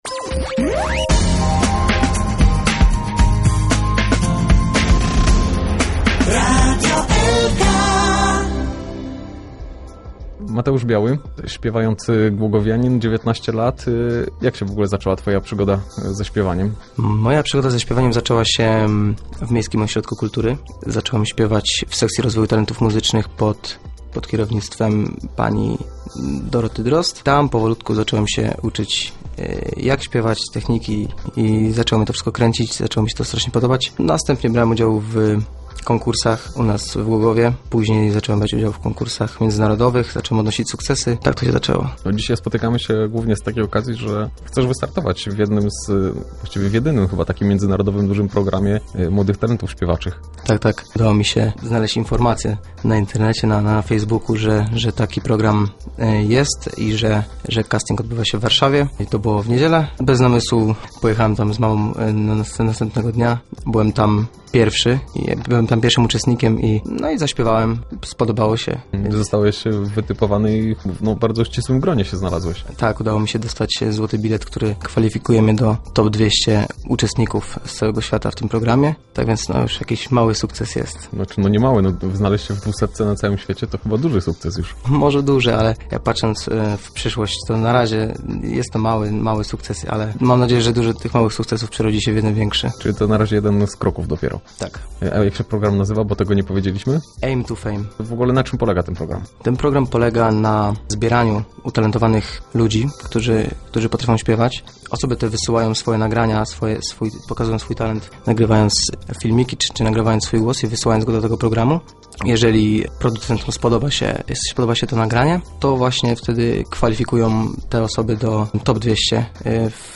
Rozmowa
na antenie Radia Elka